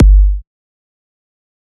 EDM Kick 1.wav